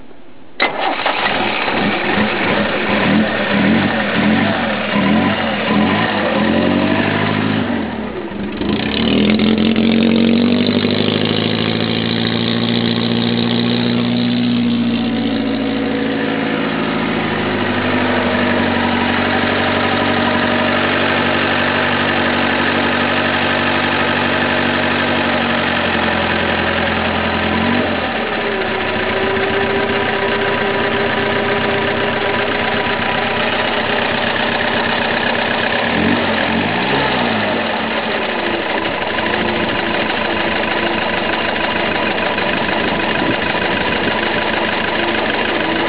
スタートの音は